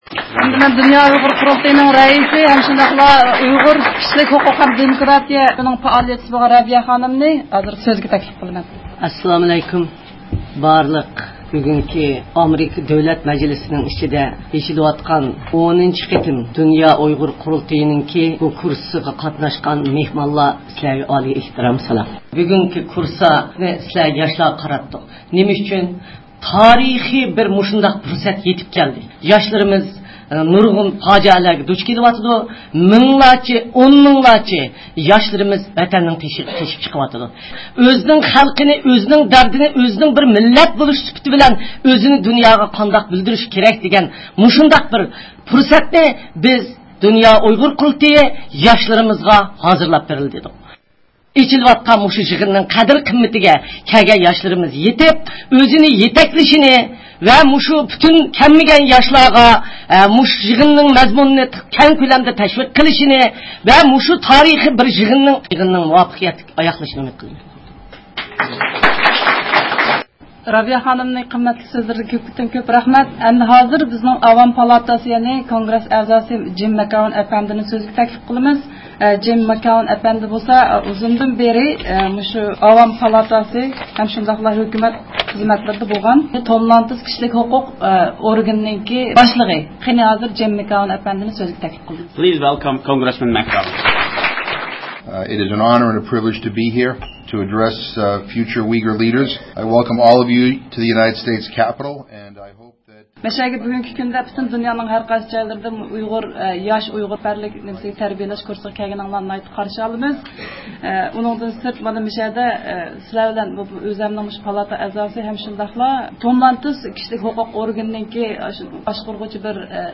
ئۆتكەن ھەپتە، ئامېرىكا دۆلەت مەجلىسى بىناسىدا دۇنيا ئۇيغۇر قۇرۇلتىيىنىڭ يېڭى بىر نۆۋەتلىك ياش ئاكتىپلىرىنى تەربىيىلەش كۇرسىنىڭ ئېچىلىش مۇراسىمى بولۇپ ئۆتكەن ئىدى.
ئاۋاز ئۇلىنىشىدىن، مەزكۇر ئېچىلىش مۇراسىمىدىكى ئەھمىيەتلىك نۇتۇقلاردىن ئۈزۈندىلەر ئاڭلايسىلەر.